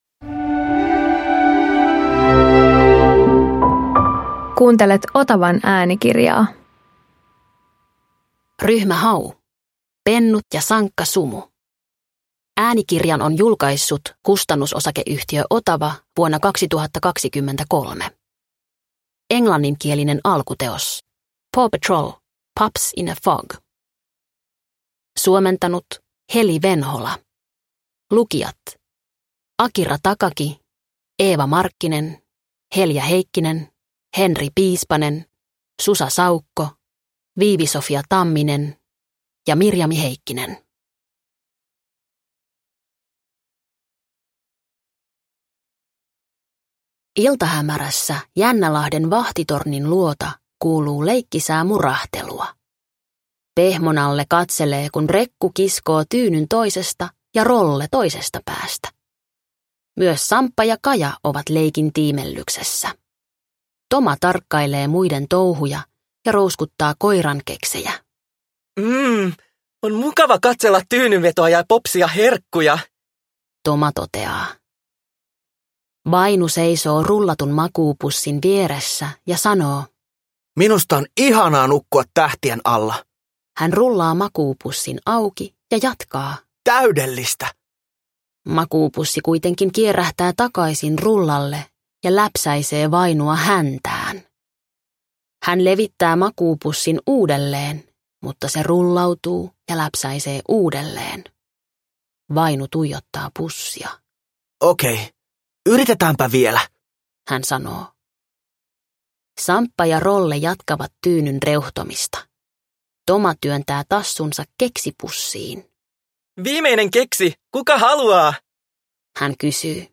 Ryhmä Hau Pennut ja sankka sumu – Ljudbok – Laddas ner